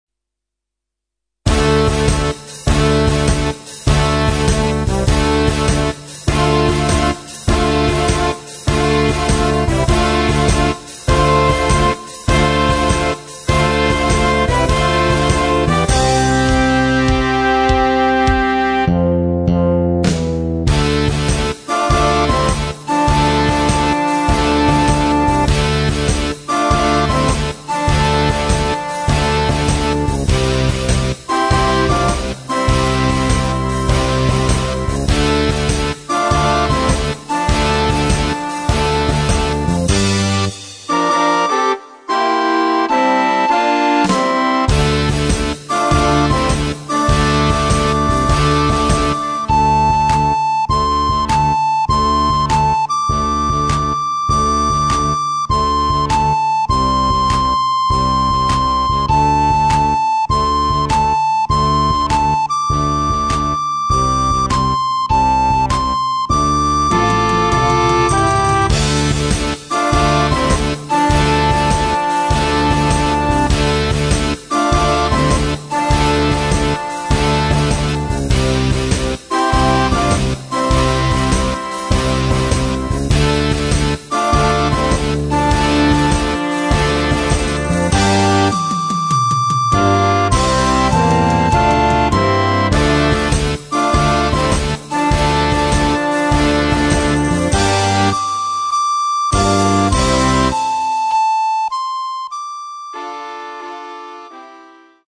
Partitions pour ensemble flexible, 4-voix + percussion.